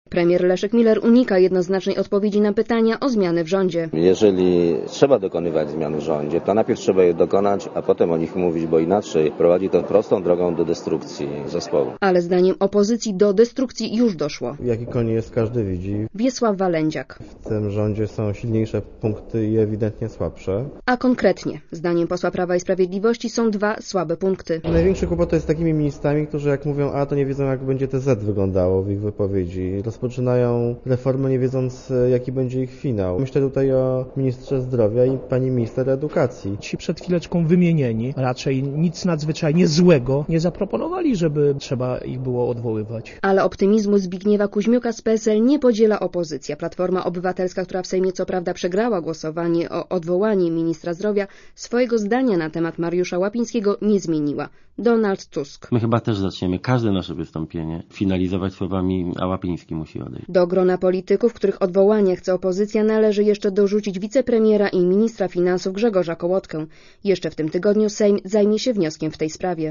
© (RadioZet) Komentarz audio